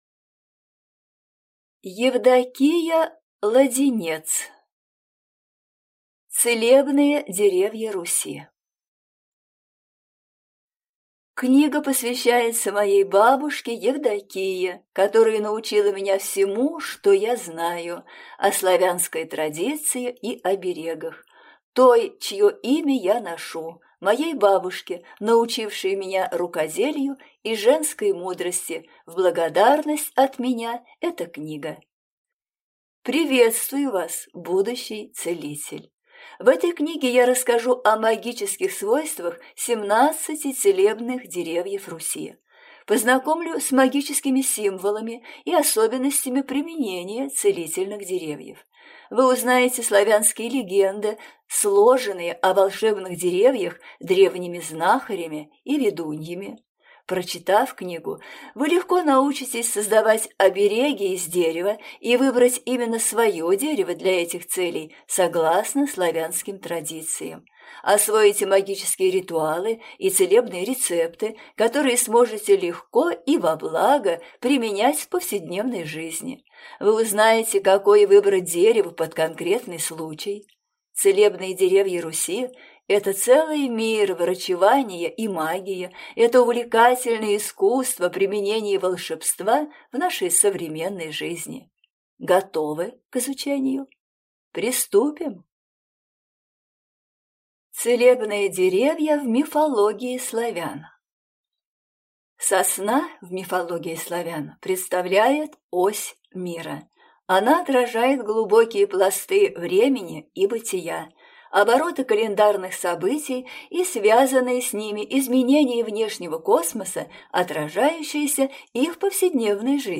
Аудиокнига Целебные деревья Руси | Библиотека аудиокниг